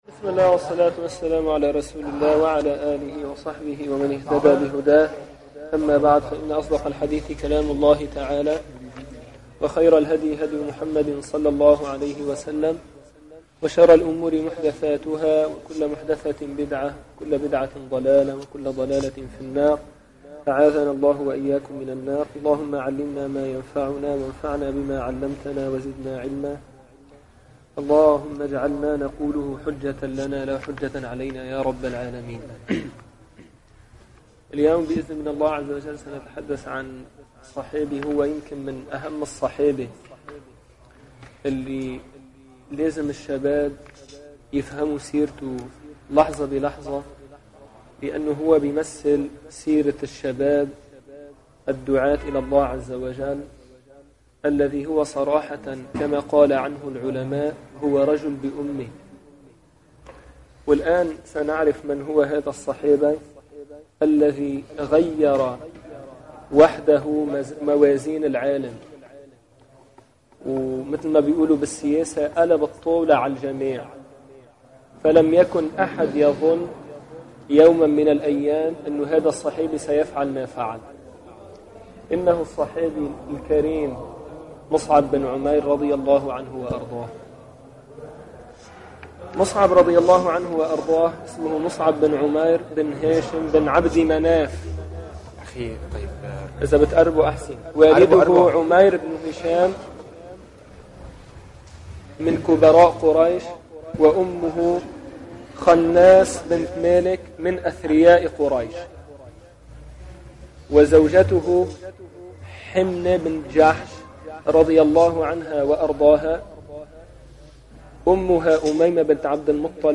من دروس مسجد القلمون الغربي الشرعية